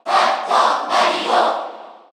Category: Crowd cheers (SSBU) You cannot overwrite this file.
Dr._Mario_Cheer_Korean_SSBU.ogg